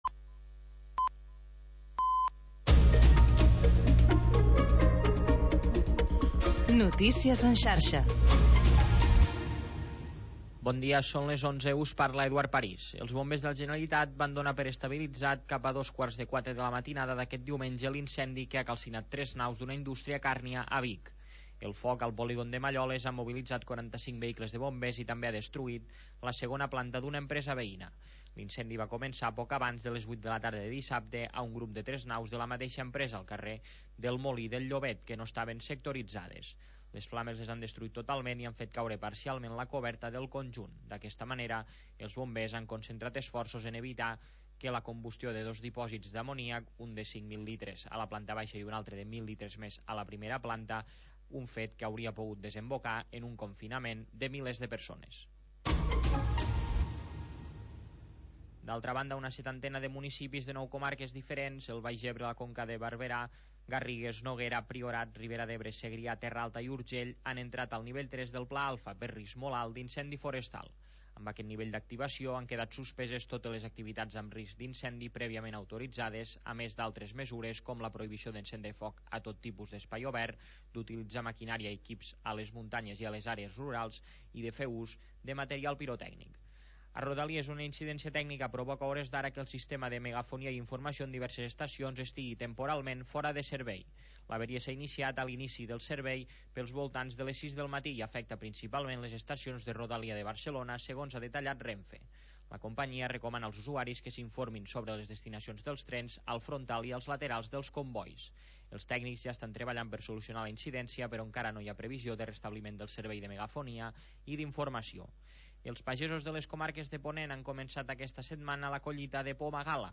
Havanera, cant de taverna i cançó marinera. 15 anys obrint una finestra al mar per deixar entrar els sons més mariners